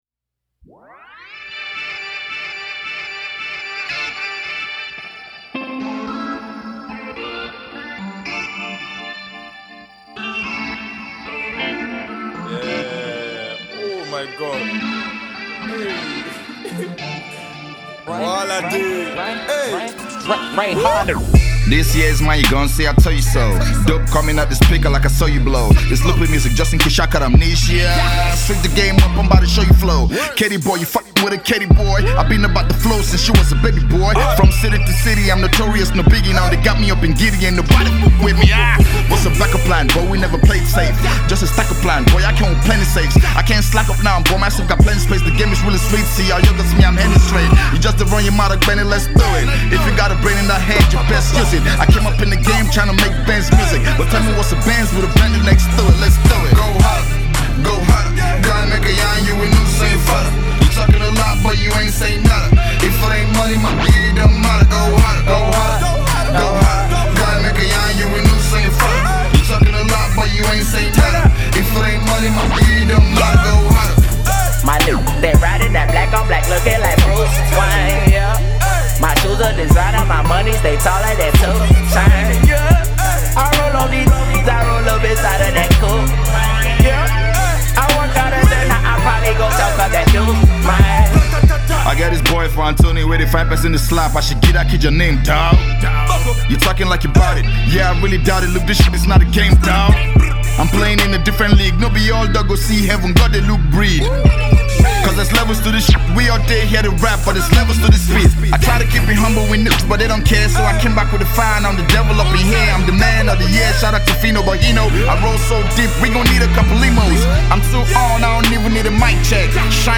Hip-Hop
its a hood banger